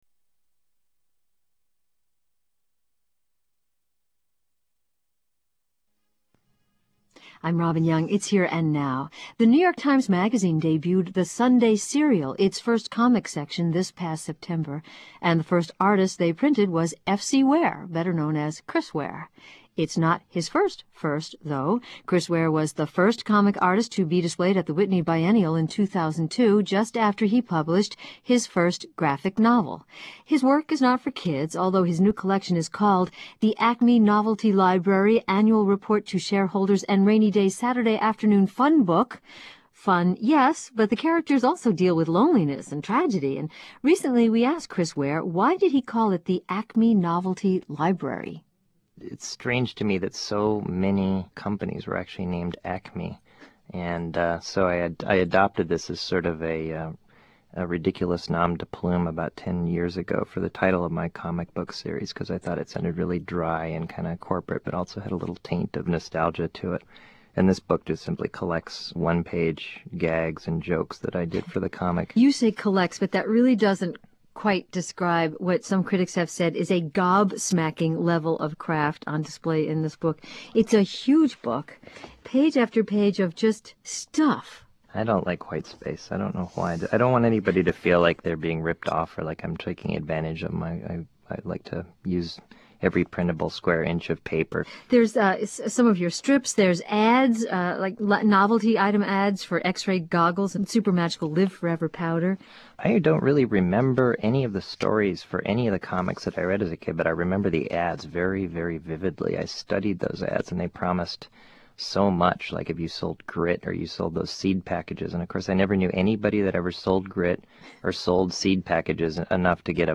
Acme Novelty Archive - Here & Now Interview